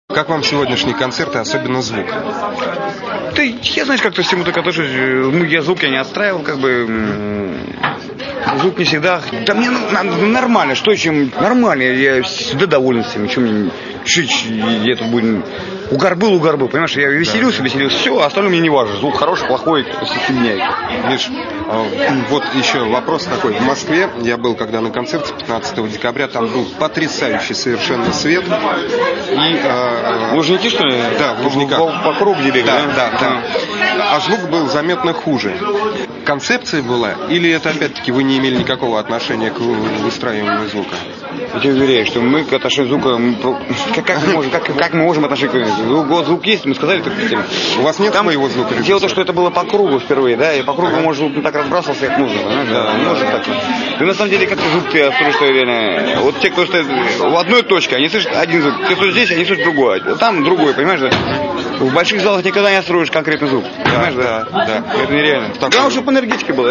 Интервью с Михаилом Горшеневым